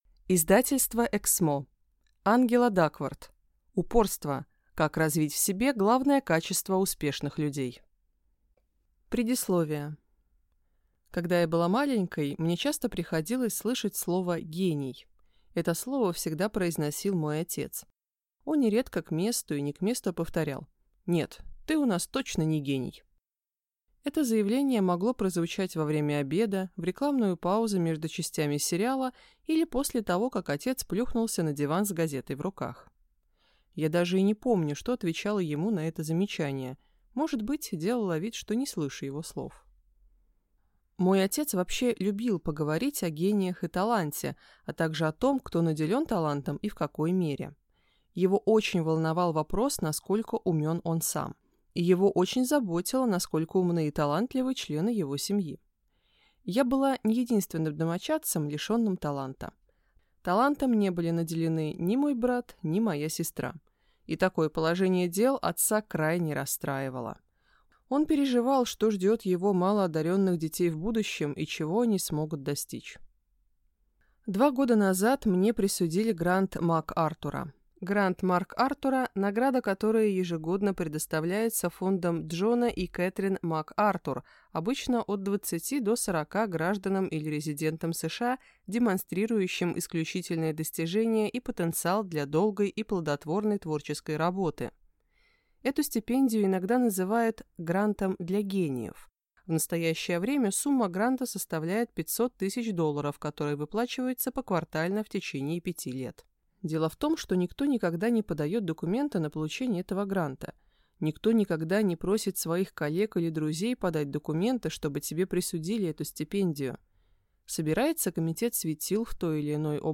Аудиокнига Упорство. Как развить в себе главное качество успешных людей | Библиотека аудиокниг